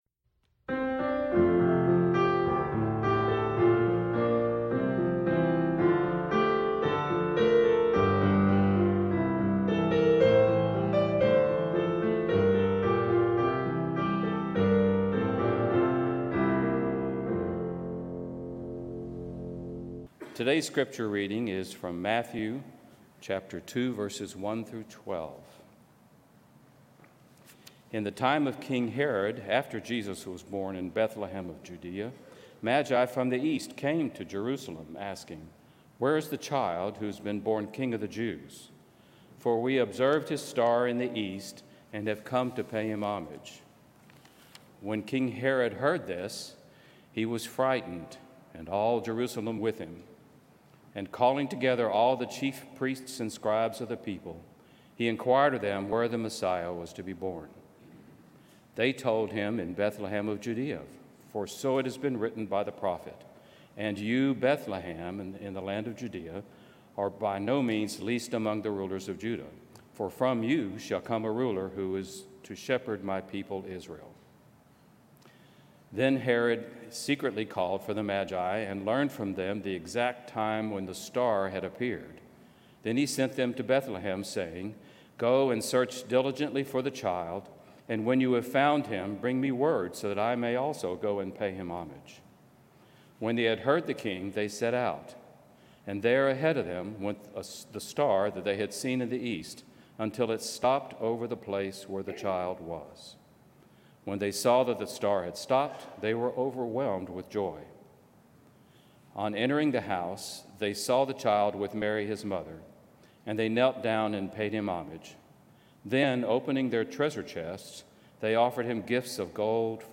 January 4, 2026 sermon audio.mp3